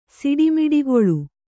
♪ siḍi miḍigoḷu